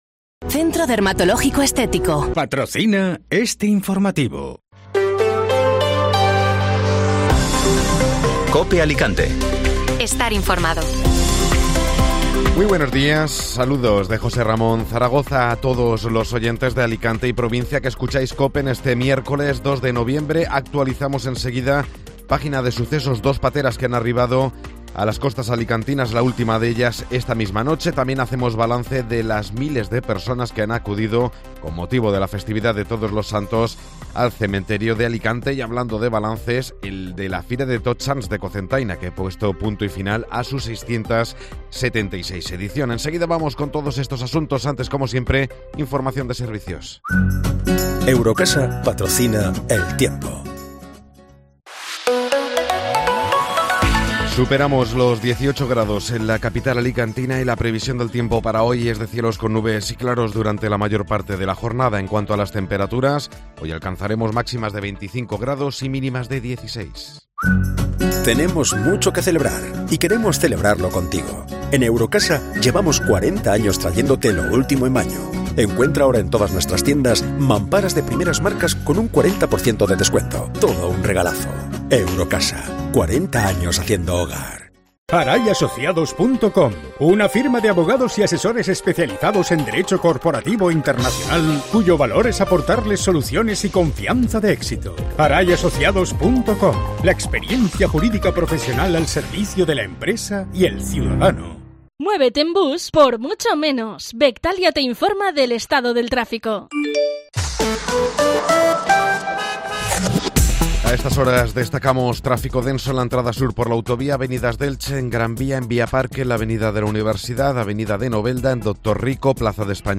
Informativo Matinal (Miércoles 2 de Noviembre)